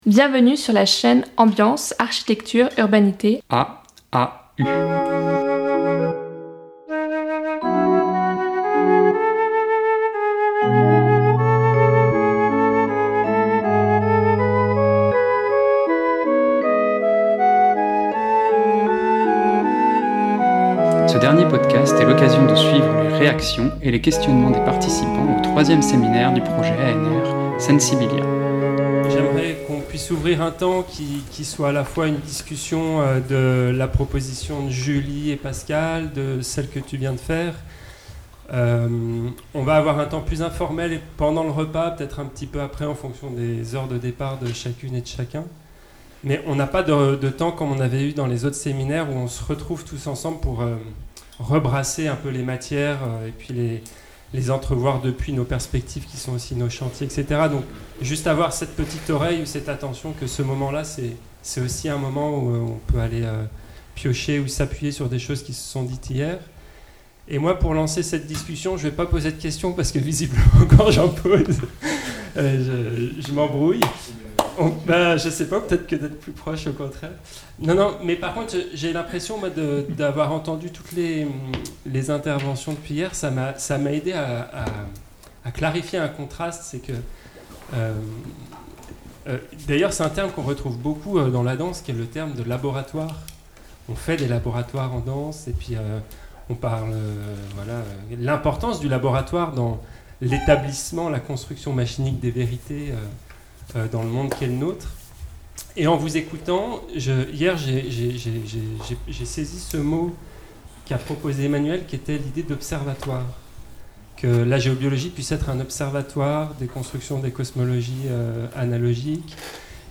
Les énigmes du sensible 3 : discussion collective | Canal U
Discussion qui prend lieu à la fin du troisième séminaire du projet ANR Sensibilia : des sensibilités minoritaires.